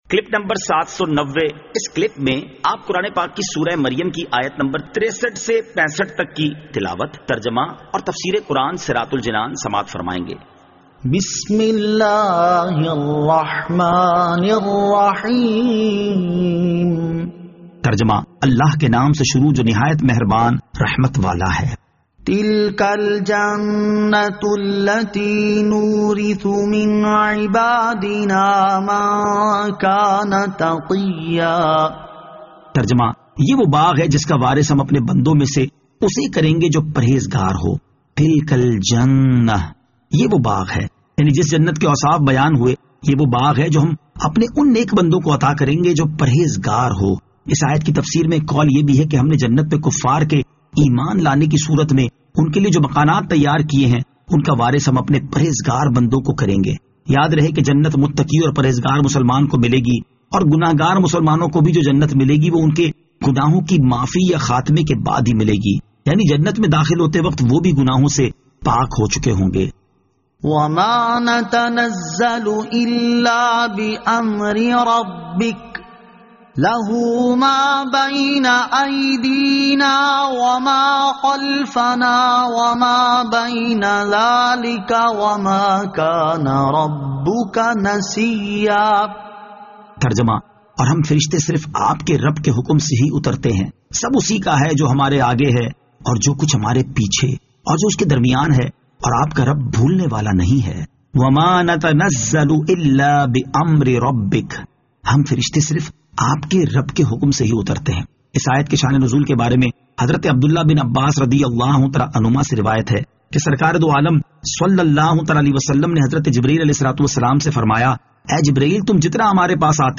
Surah Maryam Ayat 63 To 65 Tilawat , Tarjama , Tafseer